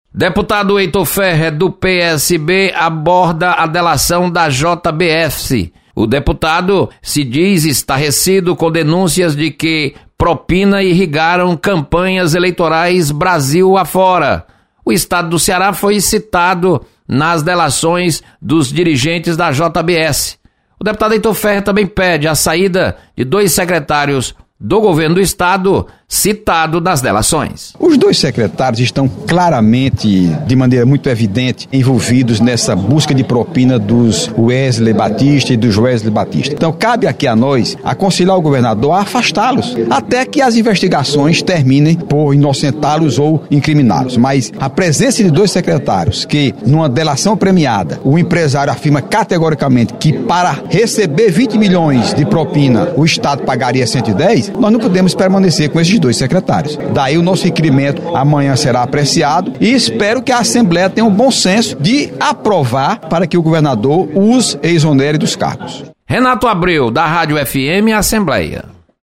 Deputado Heitor Férrer requer pedido de afastamento de secretários do Estado. Repórter